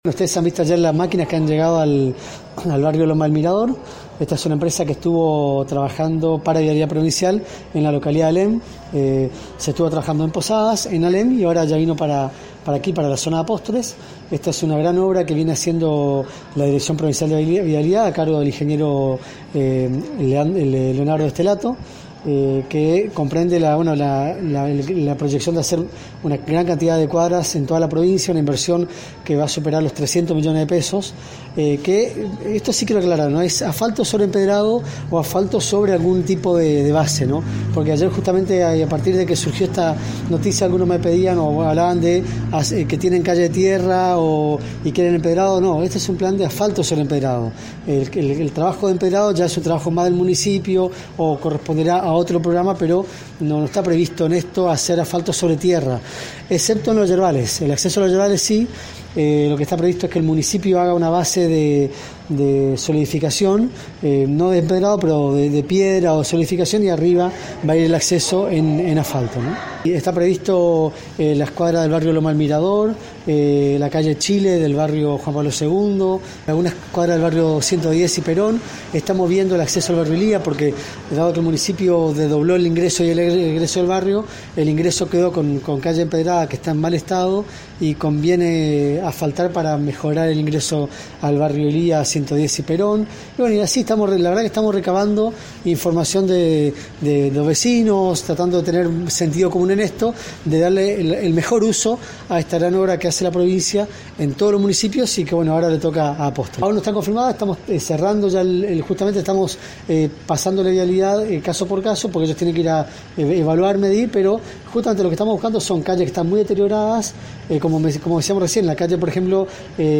En la inauguración del tinglado de la escuela Normal el ministro de Hacienda Adolfo Safrán, en charla con los medios destacó la fuerte inversión en obras del Gobierno Misionerista , como así también, adelantó los trabajos de Vialidad provincial en esta ciudad.